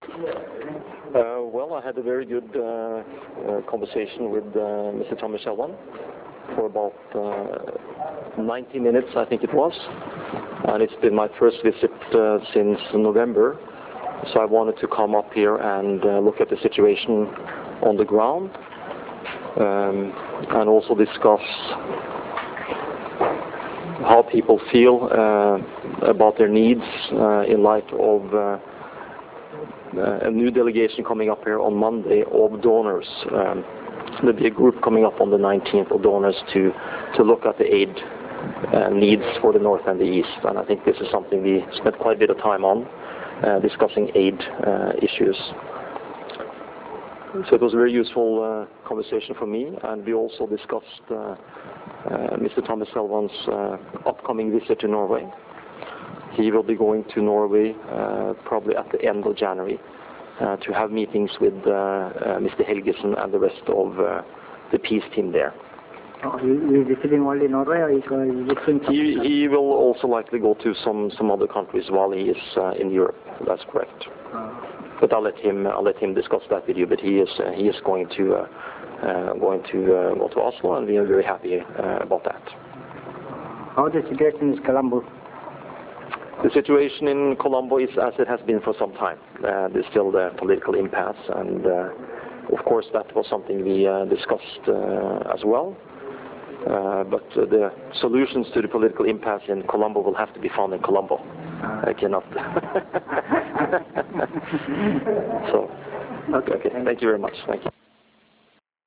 Mr. Brattskar told local presspersons after the meeting that he discussed aid issues for the northeast and Mr. Thamilchelvan's upcoming visit to Norway.